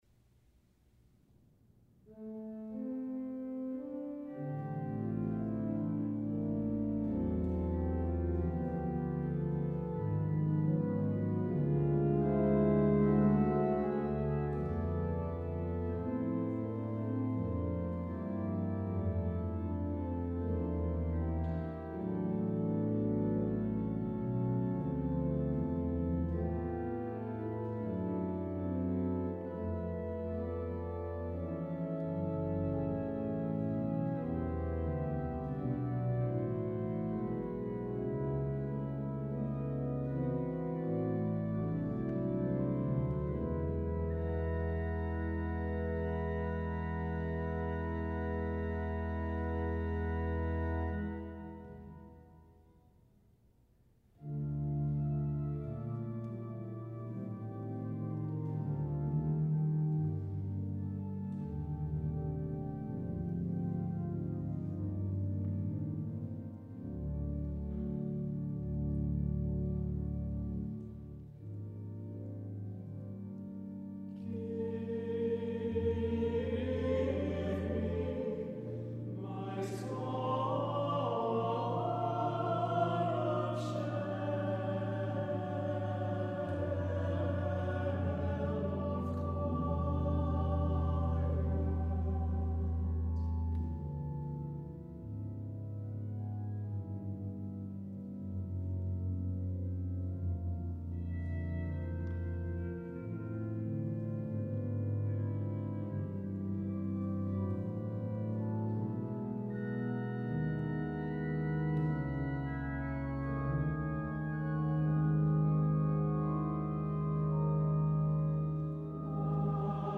• a gratifying and moving work for the advanced choir